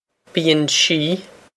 bíonn sí bee-on she
Pronunciation for how to say
bee-on she
This is an approximate phonetic pronunciation of the phrase.